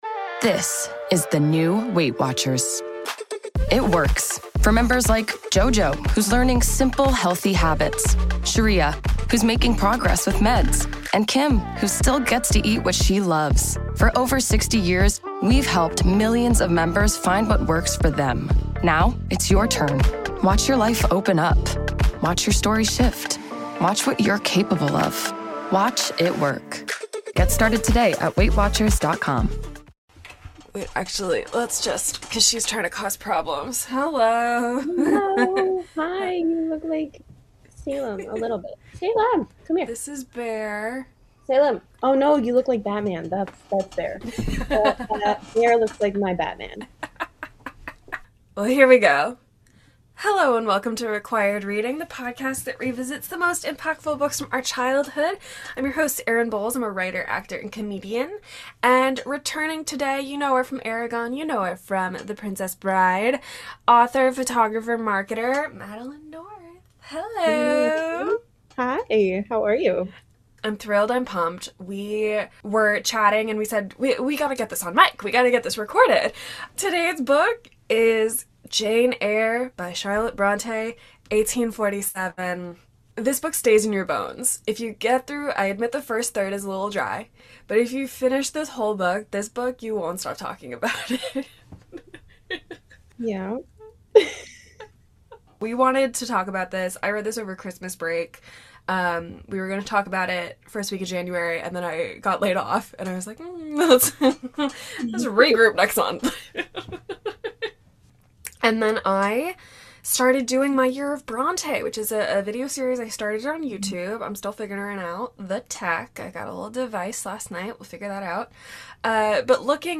Be a guest on this podcast Language: en Genres: Arts , Books , Comedy , Comedy Interviews Contact email: Get it Feed URL: Get it iTunes ID: Get it Get all podcast data Listen Now...